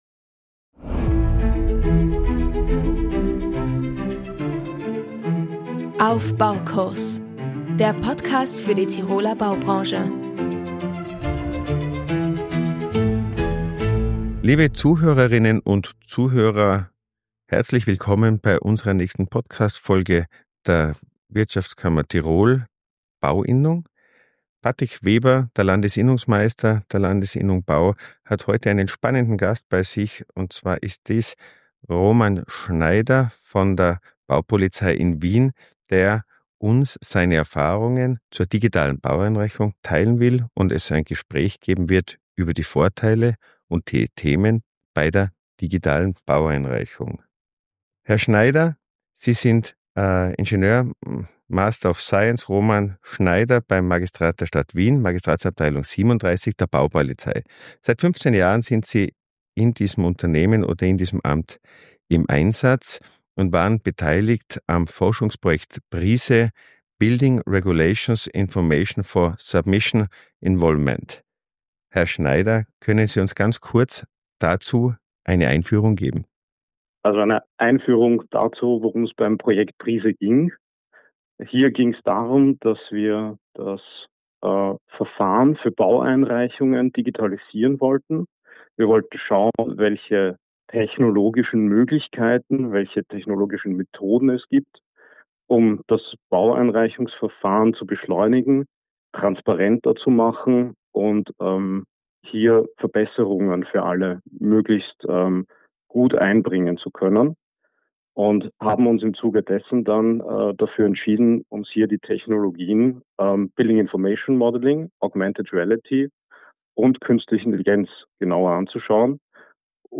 Ein spannender Austausch über Herausforderungen, Chancen und die Zukunft moderner Genehmigungsprozesse.